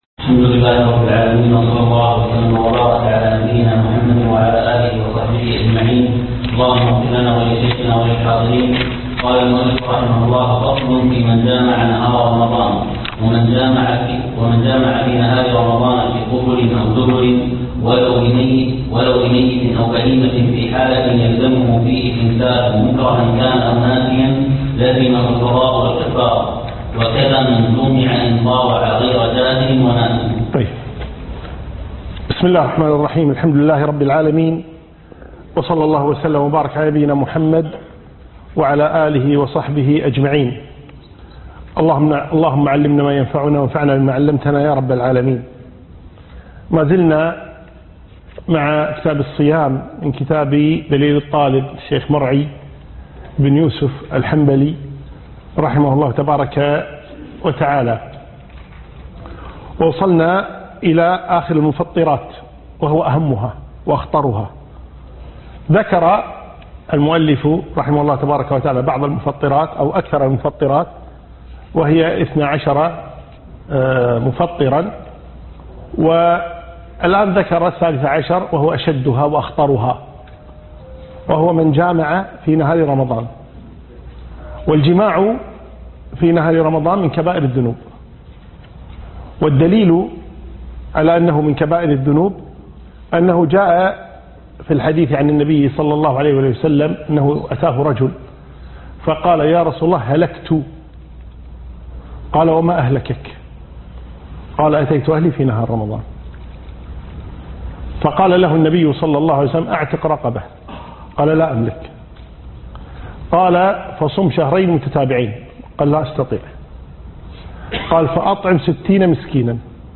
الدرس الثالث (فقه الصيام)